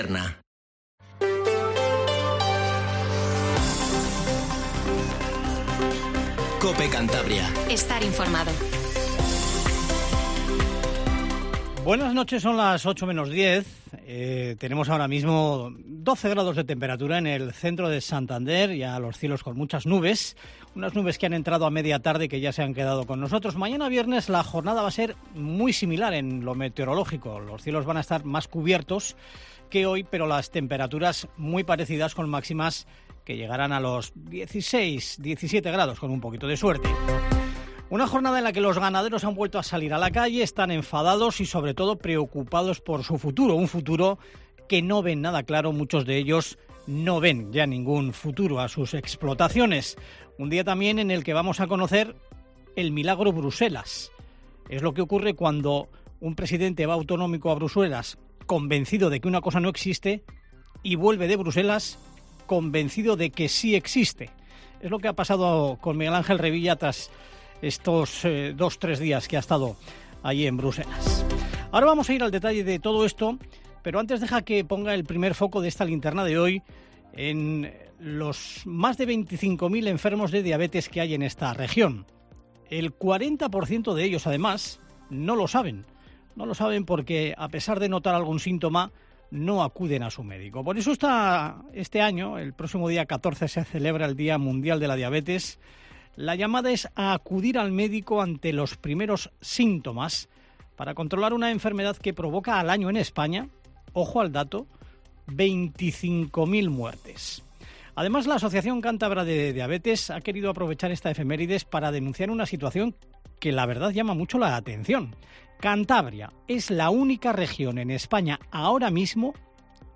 Informativo Tarde COPE CANTABRIA